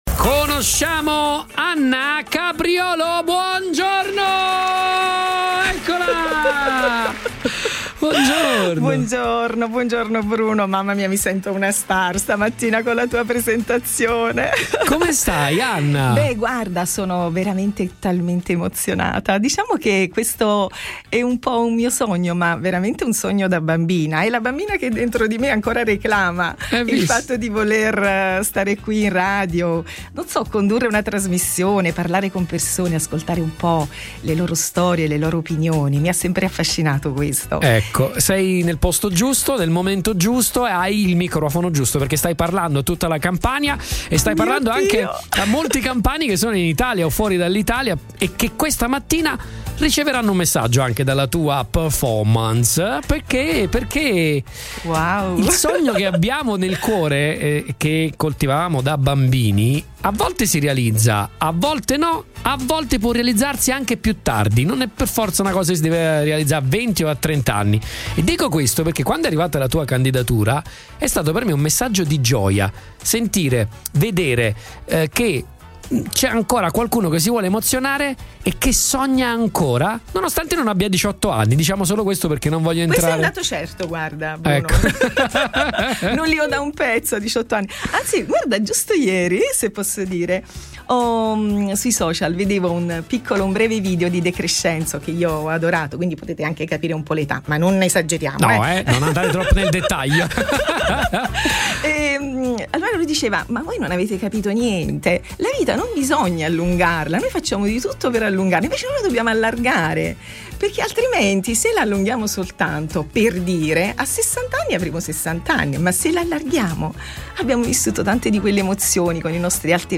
Come ogni giovedi anche ieri giovedi 5 giugno si sono sfidate due voci aspiranti speakers per Punto Nuovo New Talent.